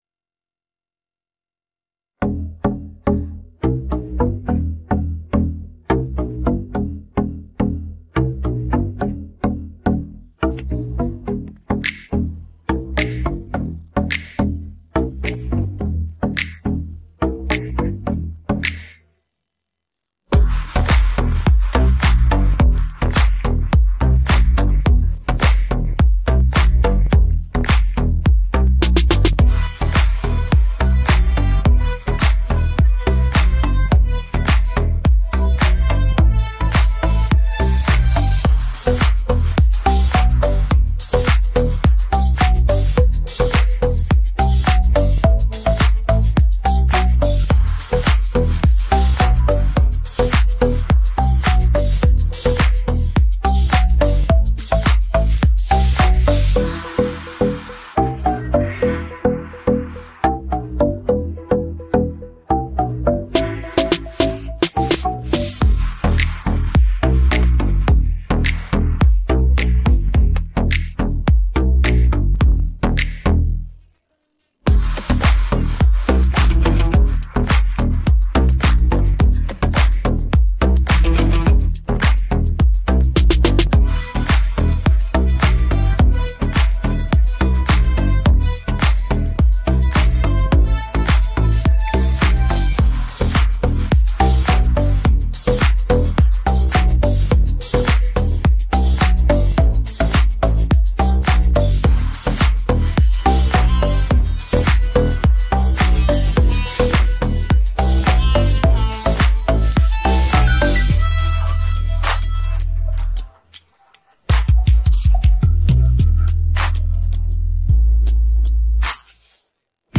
Караоке версія